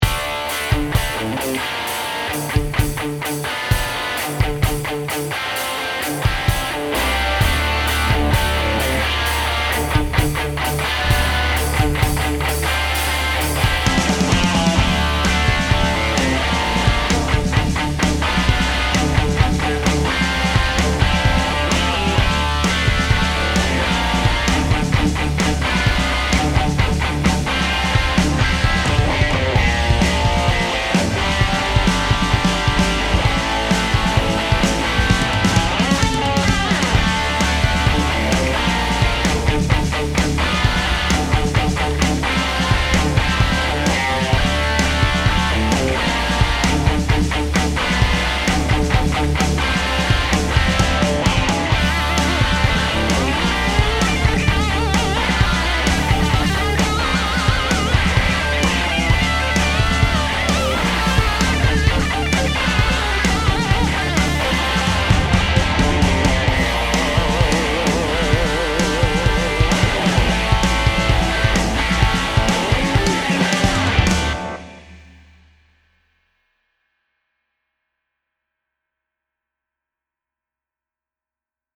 After a long time without playing at all, I made this little song after dinner in Montana...revisiting the 80s :)